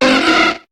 Cri de Goélise dans Pokémon HOME.